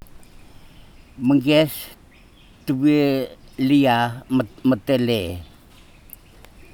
Storyboard elicitation of argument structure alternations (Storyboard: The old bench).
digital wav file recorded at 44.1 kHz/16 bit on Marantz PMD 620 recorder; ELAN eaf file
Sesivi, Ambrym, Vanuatu